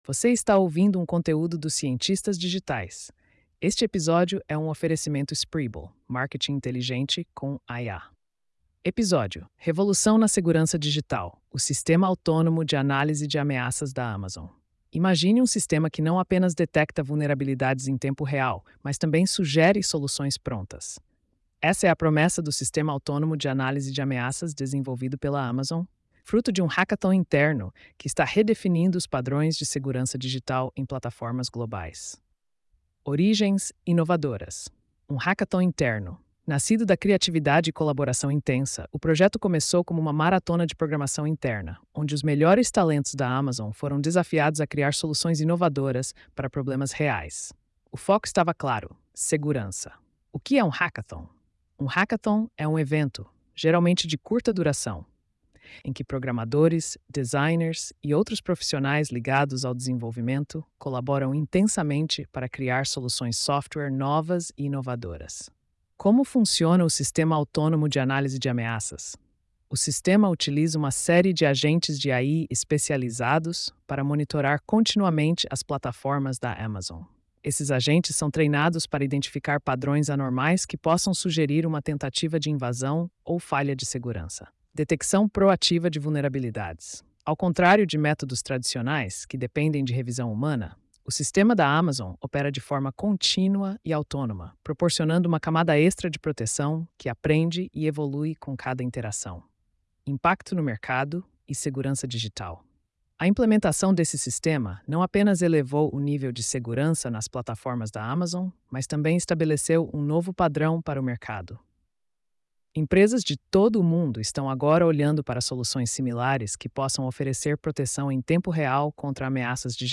post-4568-tts.mp3